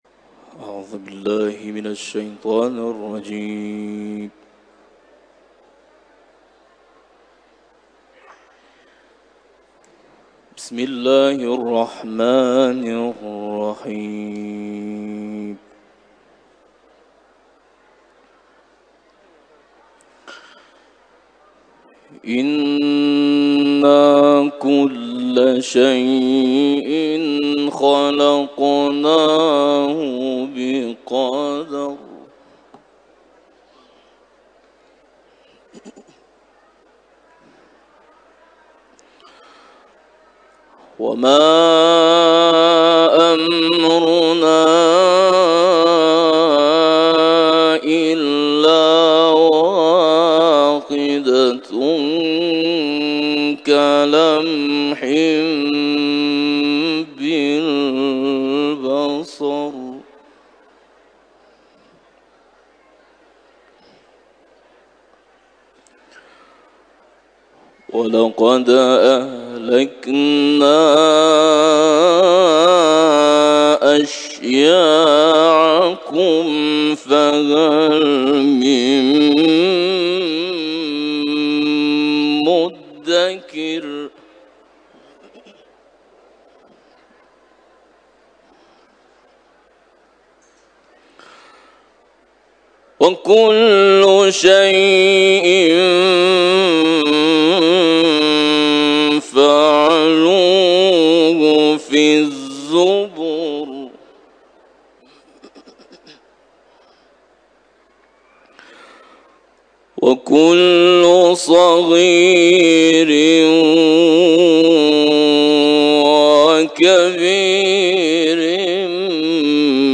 İranlı kârinin Kur’an-ı Kerim tilaveti
Etiketler: tilavet ، İranlı kâri ، Kuran tilaveti ، Rahman suresi